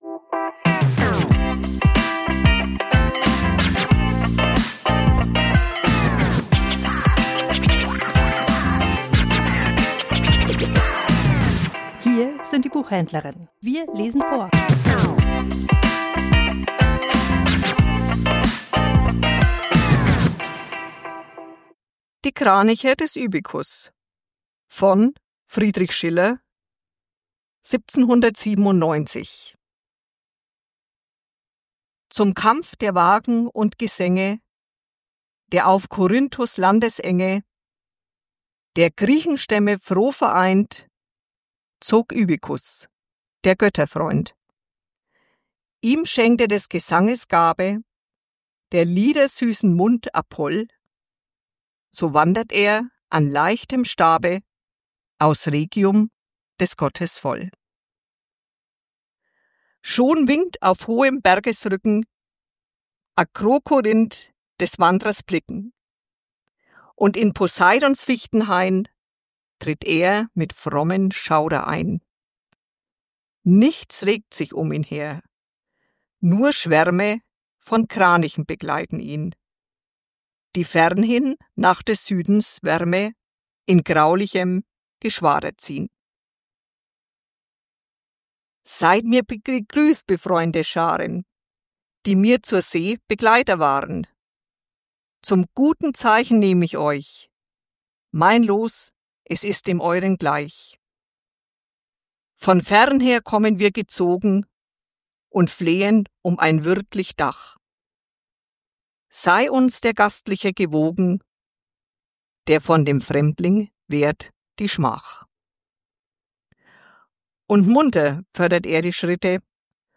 Vorgelesen: Die Kraniche des Ibykus ~ Die Buchhändlerinnen Podcast